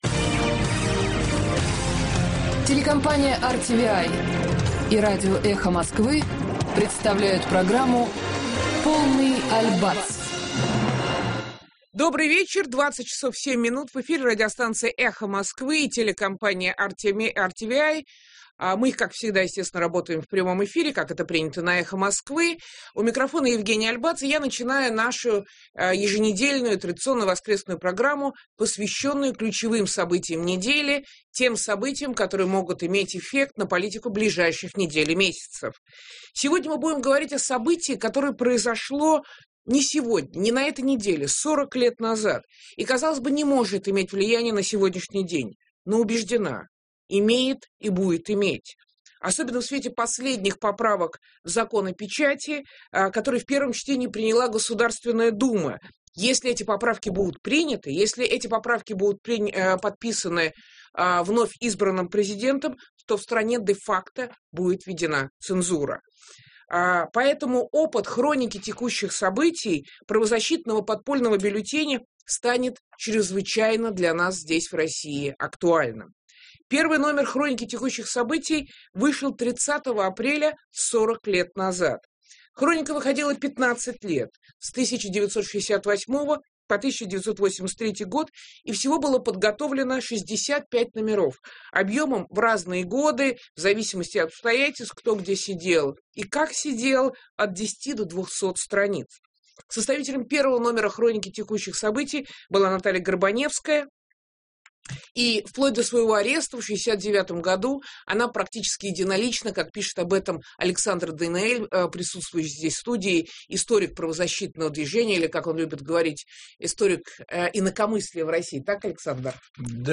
Добрый вечер, 20.07, в эфире радиостанция «Эхо Москвы» и телекомпания RTVi, мы работаем в прямом эфире, как это принято на «Эхо Москвы», у микрофона Евгения Альбац, и я начинаю нашу традиционную еженедельную воскресную программу, посвященную ключевым событиям недели, тем событиям, которые могут иметь эффект на политику ближайших недель и месяцев. Сегодня мы будем говорить о событии, которое произошло не сегодня - 40 лет назад, и, казалось бы, не может иметь влияние на сегодняшний день.